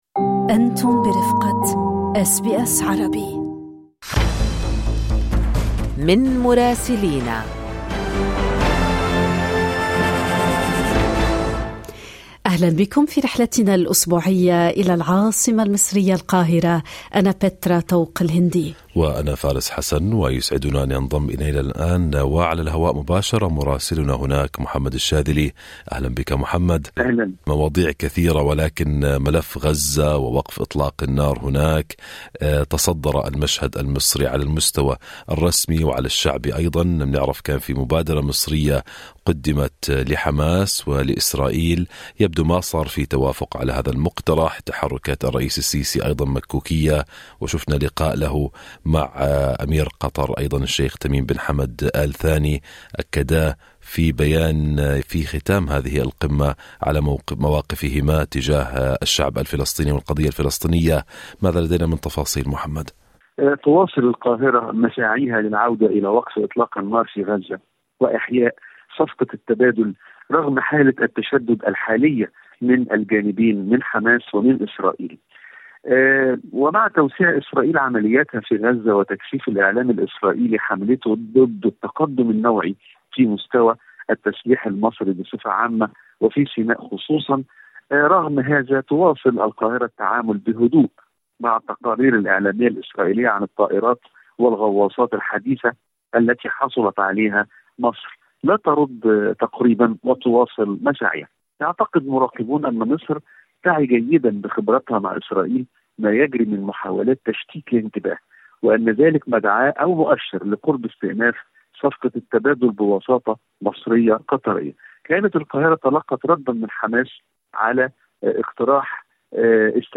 المزيد في التقرير الصوتي أعلاه.